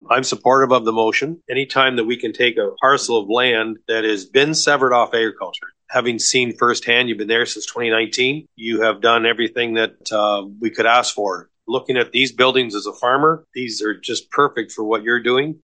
That’s following a discussion at council’s meeting last Tuesday (February 20th).
In closing, Mayor Klopp expressed gratitude for the constructive dialogue and reiterated the importance of adhering to planning regulations while also recognizing the value of supporting local businesses and development initiatives within the community.
february-20-bw-council-meeting-outbuliding-plumbing-bylaw-klopp.mp3